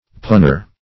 punner - definition of punner - synonyms, pronunciation, spelling from Free Dictionary Search Result for " punner" : The Collaborative International Dictionary of English v.0.48: Punner \Pun"ner\, n. A punster.